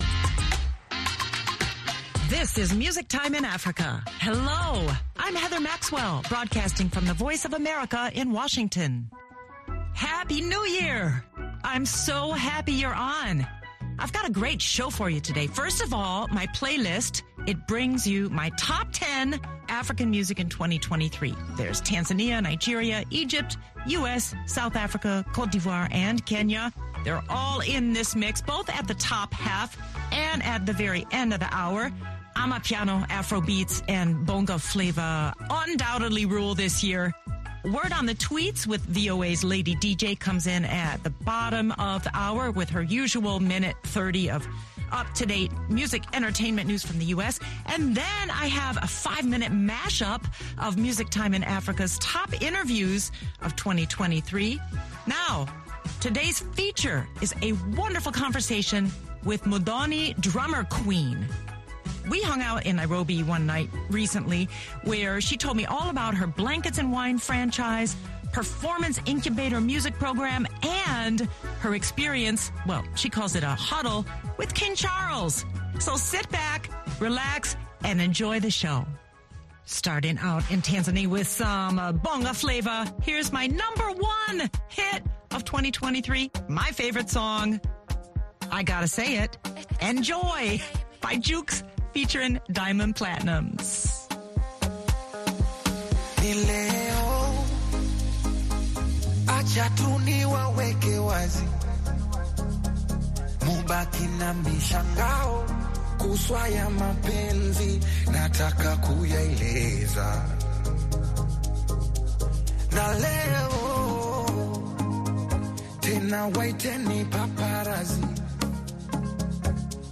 Music Time in Africa is VOA’s longest running English language program. Since 1965 this award-winning program has featured pan African music that spans all genres and generations.